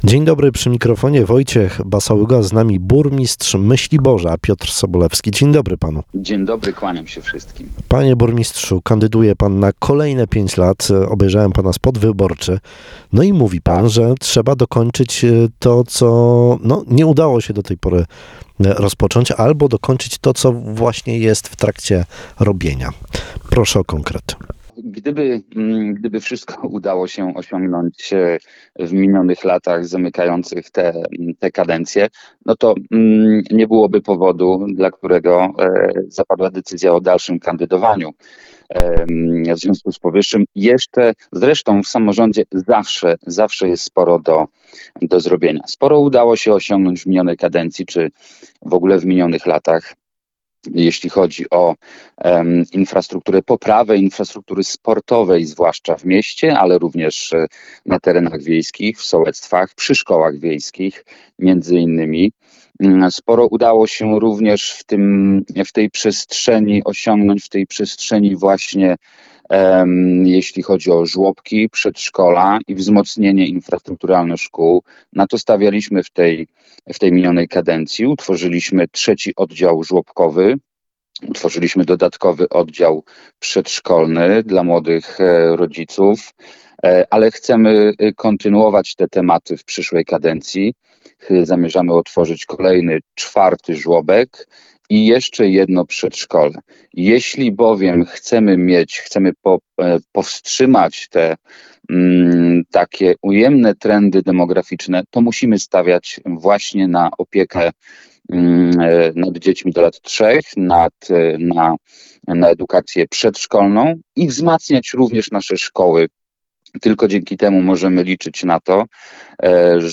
Utworzenie drugiej plaży miejskiej w Myśliborzu zapowiedział dziś rano w Twoim Radiu burmistrz Piotr Sobolewski. W nowej kadencji zamierza przyciągnąć do gminy znacznie więcej turystów.